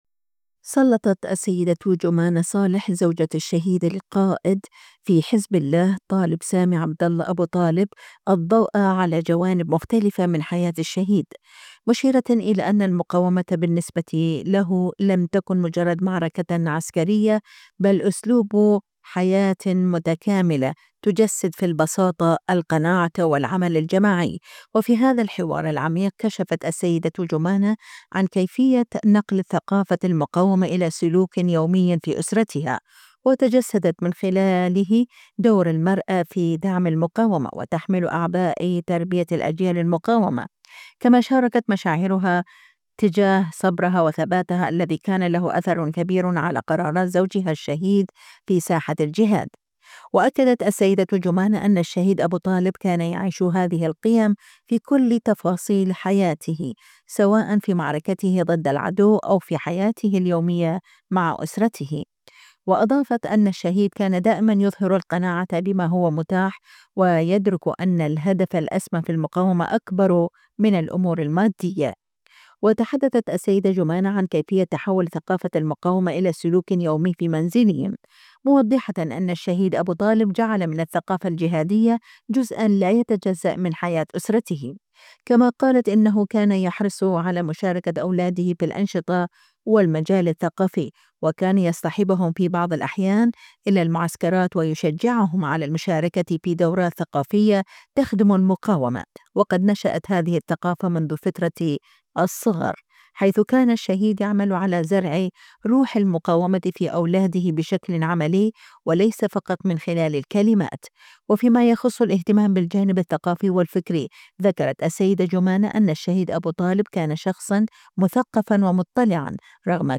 الكوثر - مقابلات